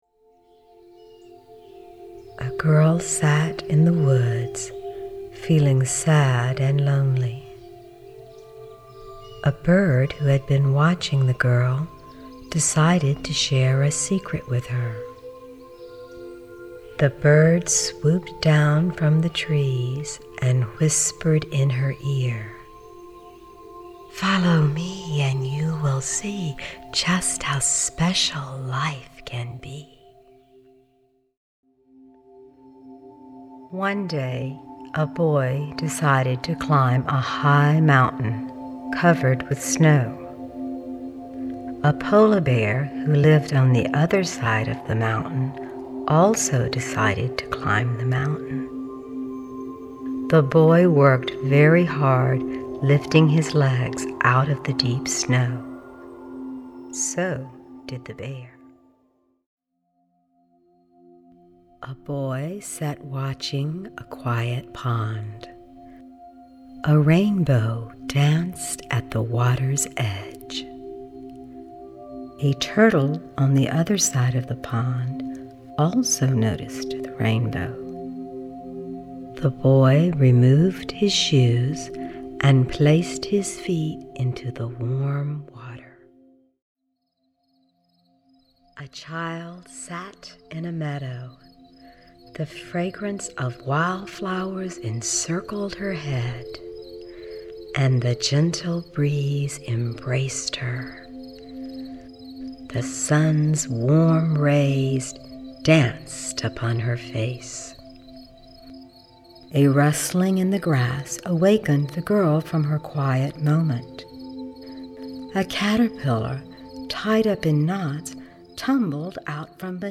Indigo Dreams is a 60-minute bedtime relaxation audiobook designed to entertain your child while introducing them to relaxation and stress-management techniques.
Female narration is accompanied by soothing sounds of crickets, gentle breezes, and forest animals.